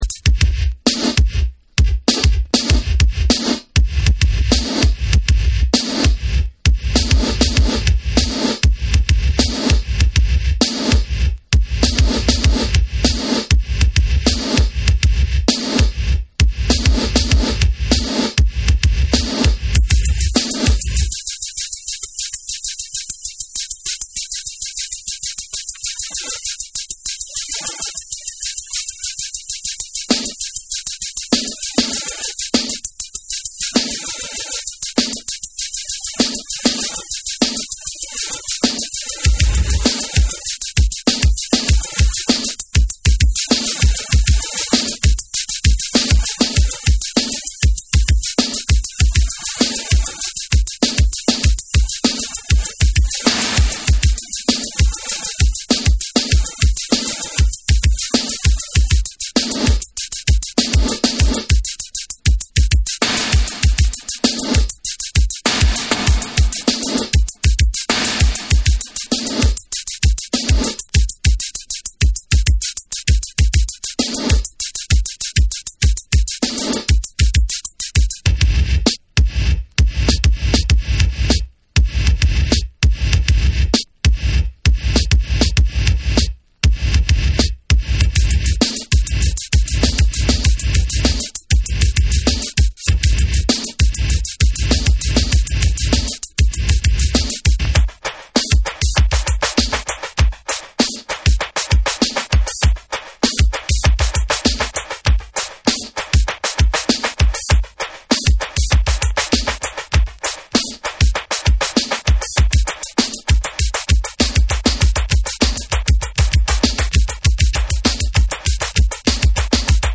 BREAKBEATS / HIP HOP